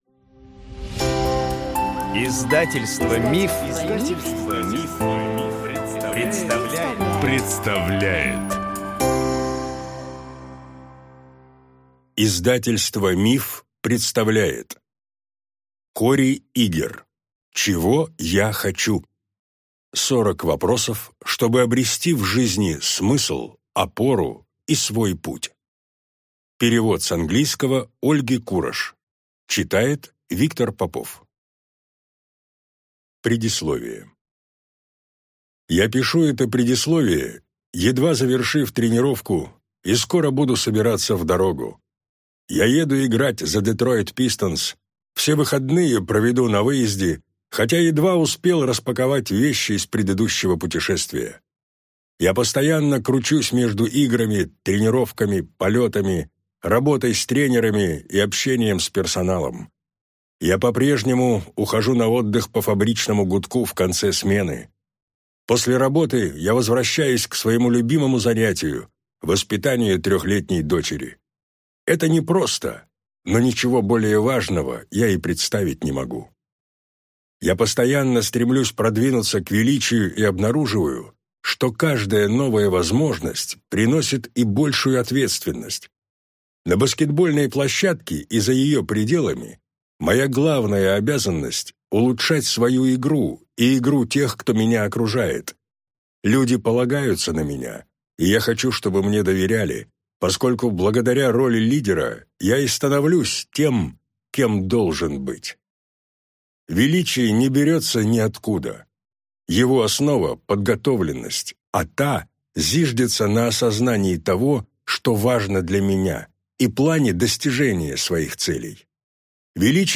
Аудиокнига Чего я хочу? 40 вопросов, чтобы обрести в жизни смысл, опору и свой путь | Библиотека аудиокниг